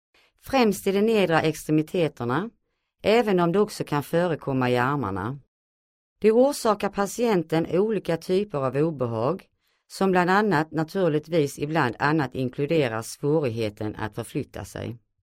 Swedish female voice over